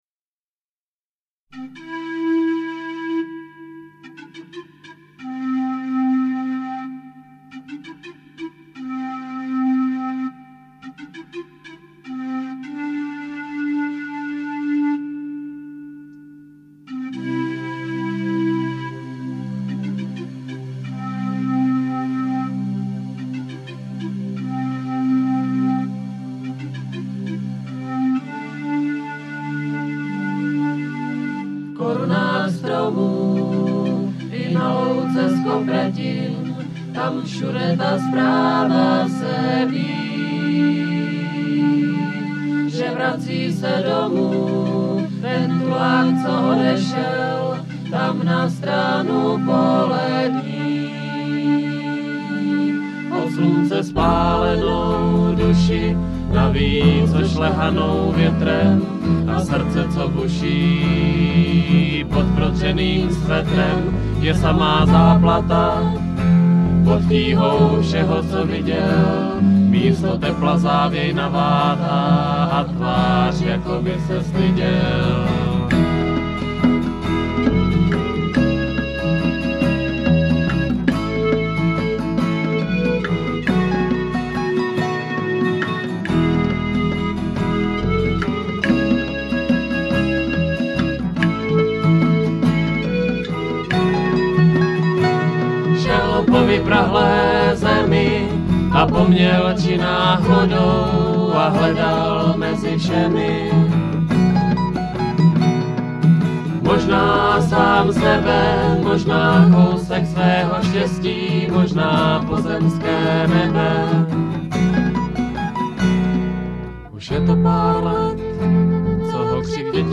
DEMO-DOMA 1996, MP3